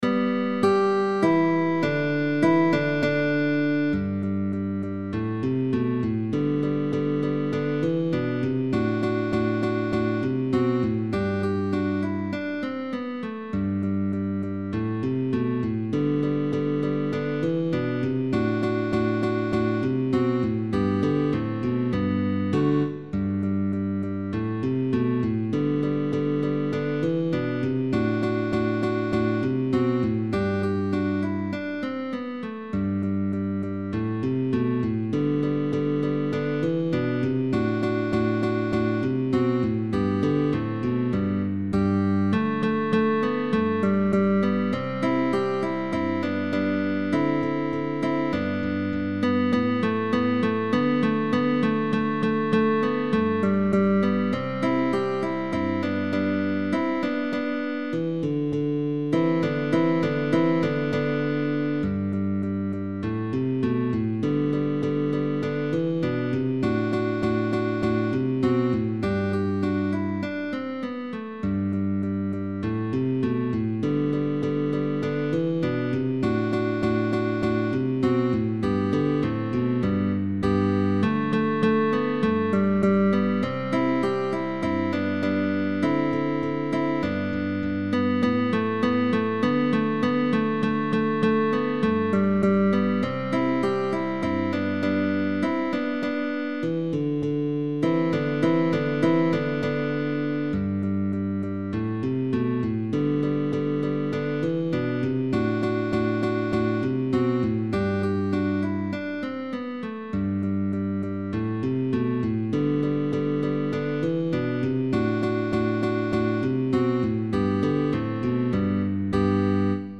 GUITAR TRIO